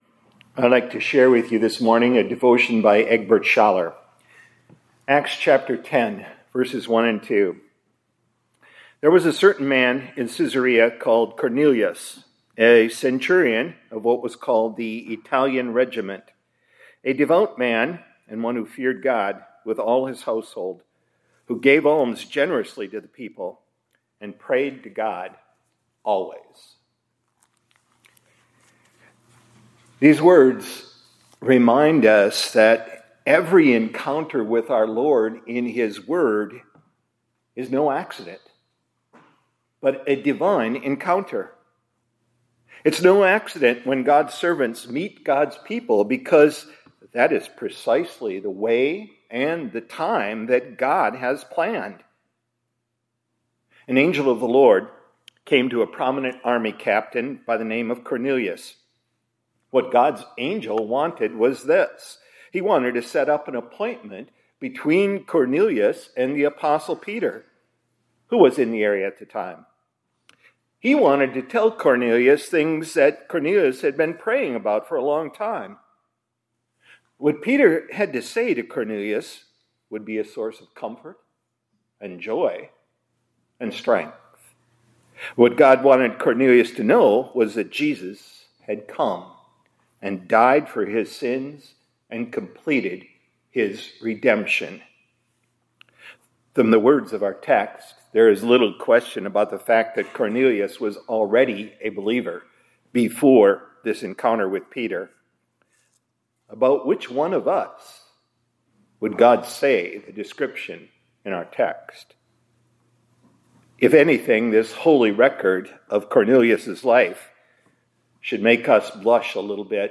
2026-02-10 ILC Chapel — Gos Chooses Sinners to Proclaim His Gospel